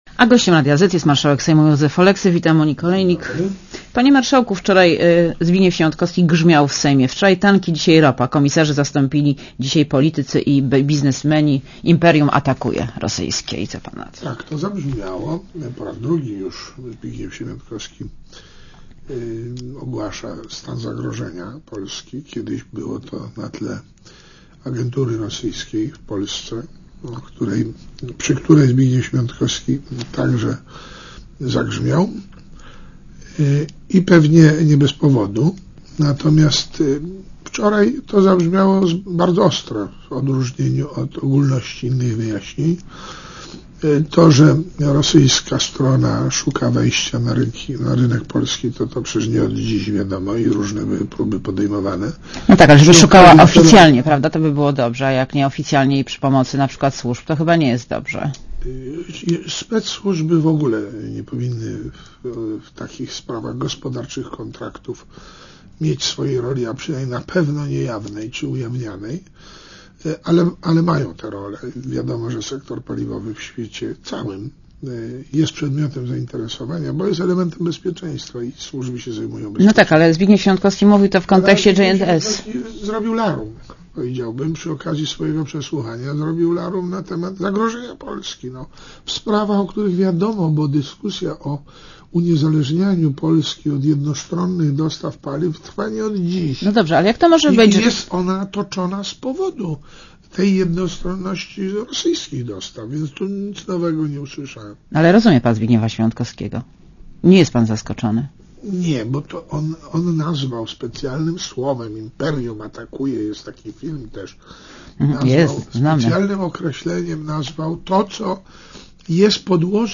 Monika Olejnik rozmawia z marszałkiem Sejmu, Józefem Oleksym